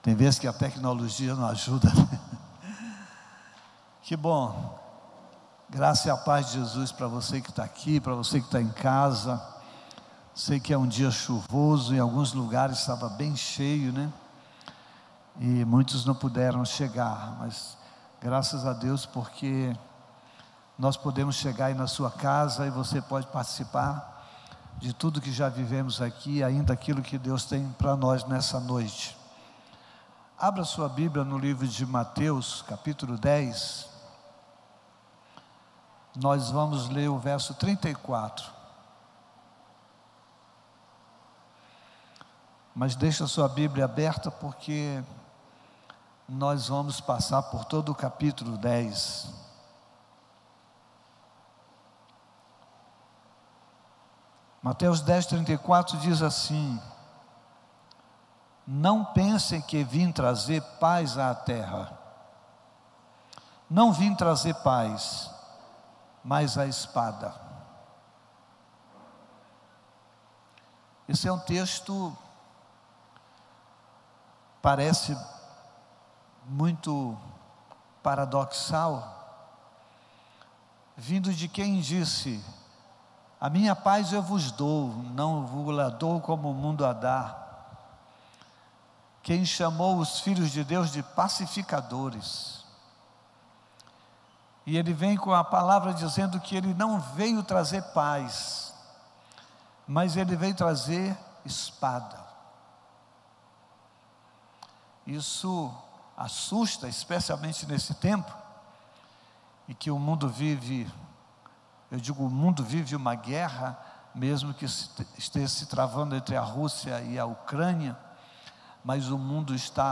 Culto presencial e transmissão on-line aos domingos às 18 h.